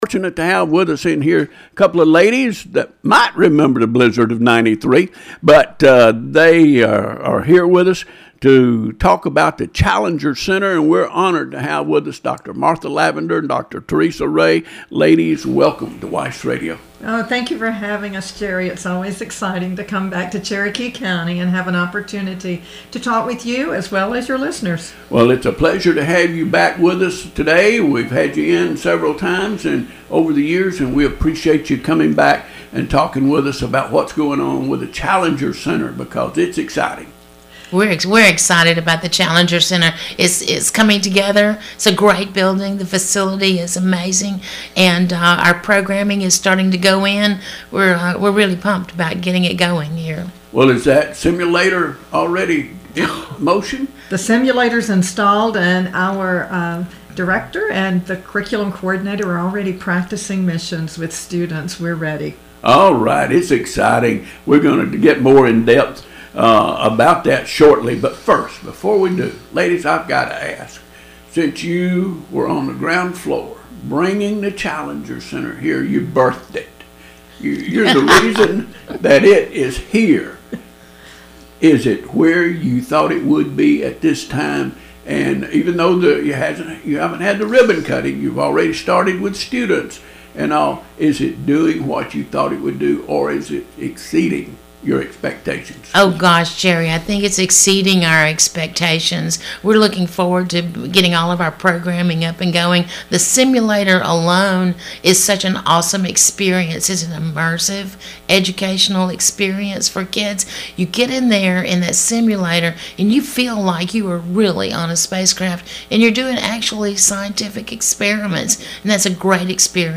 Challenger Learning Center Leaders Discuss New STEM Facility and Regional Student Challenge on WEIS Centre- Representatives of the Challenger Learning Center of Northeast Alabama visited the WEIS Radio studio Thursday morning, March 12, to discuss progress on the region’s newest STEM education facility and to announce a regional academic challenge designed to engage middle school […]